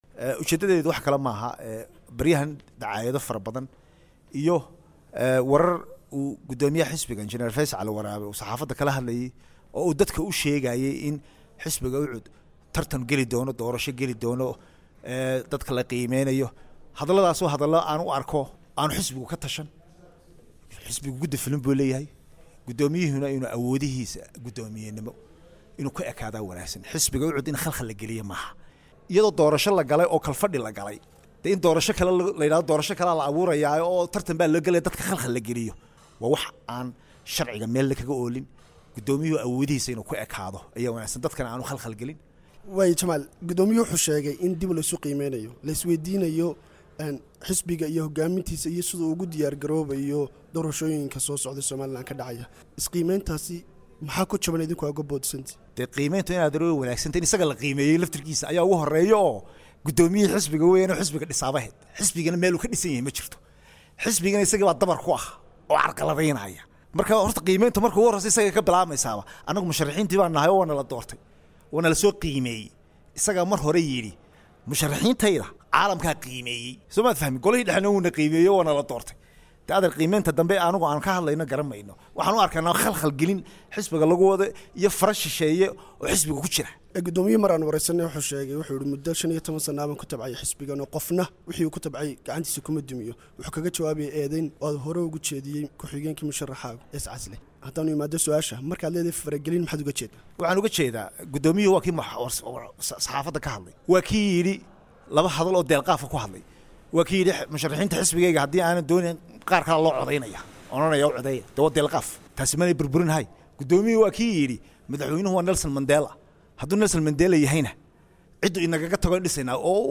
Embed share Wareysi